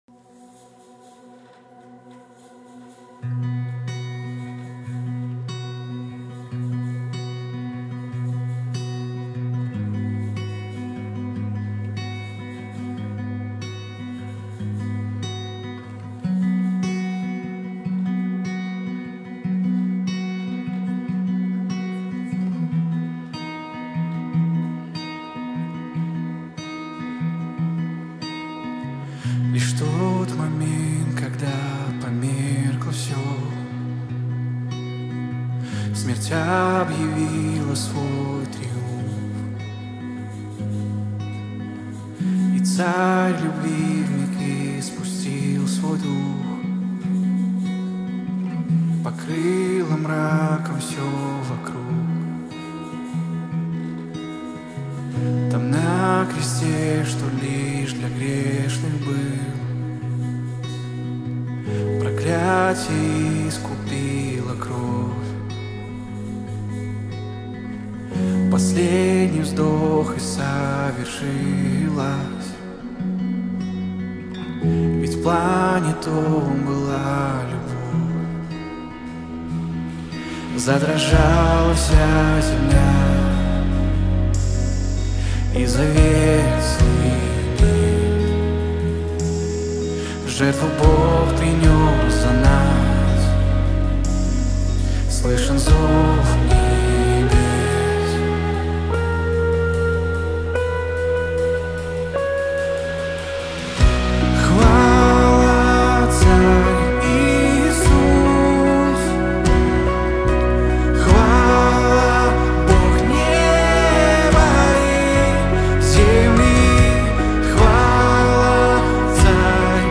3095 просмотров 773 прослушивания 63 скачивания BPM: 148